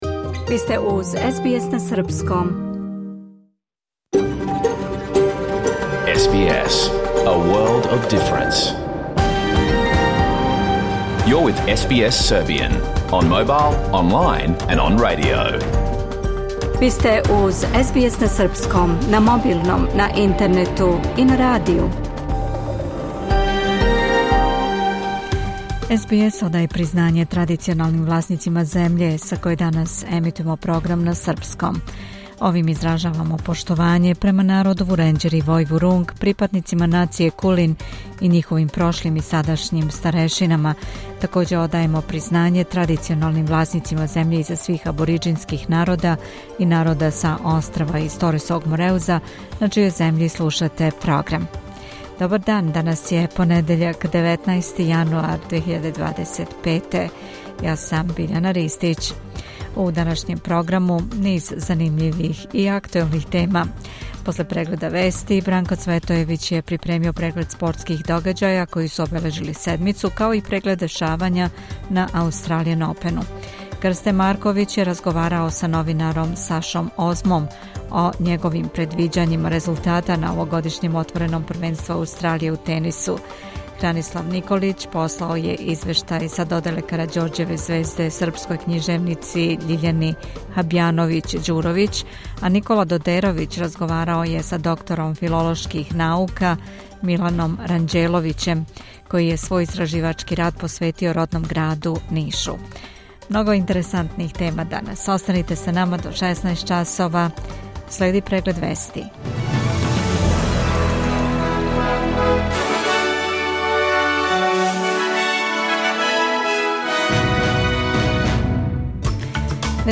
Програм емитован уживо 19. јануара 2026. године